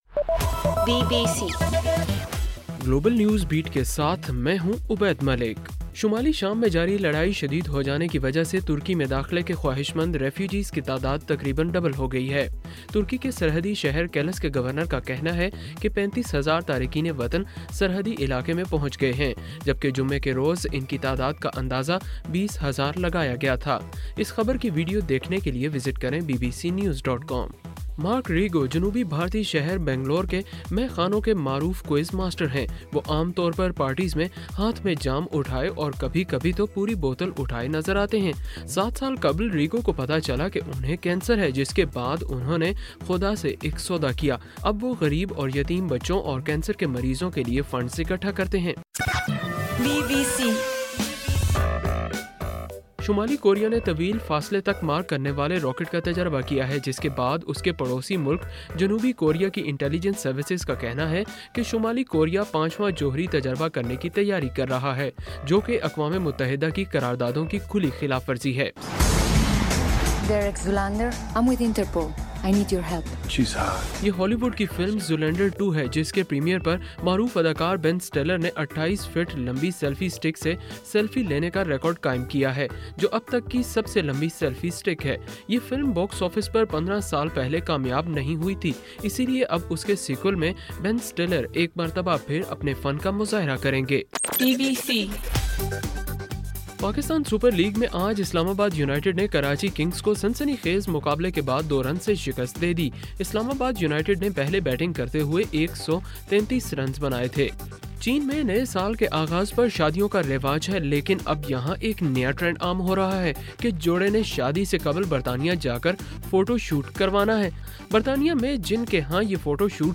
گلوبل نیوز بیٹ بُلیٹن اُردو زبان میں رات 8 بجے سے صبح 1 بجے تک ہر گھنٹے کے بعد اپنا اور آواز ایف ایم ریڈیو سٹیشن کے علاوہ ٹوئٹر، فیس بُک اور آڈیو بوم پر ضرور سنیے۔